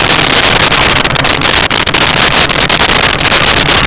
Sfx Pod Chop A Combo Loop
sfx_pod_chop_a_combo_loop.wav